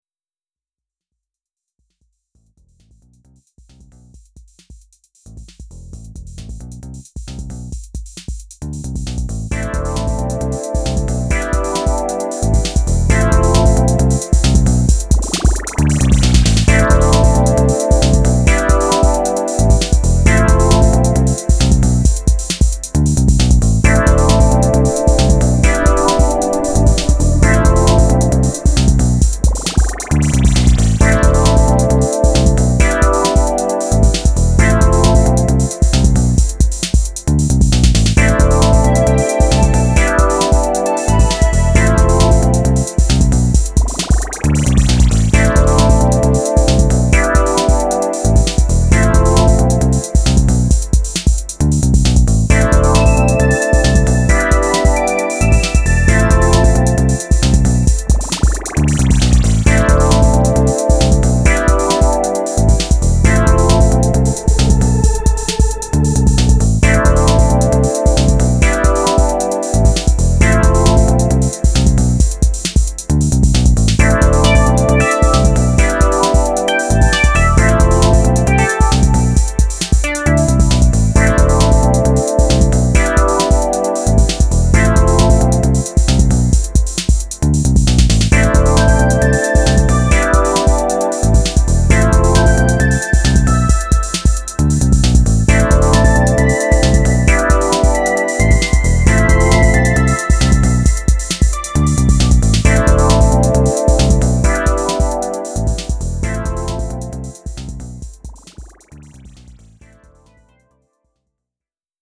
Electrofunk, Bass & Technobreaks VINYLs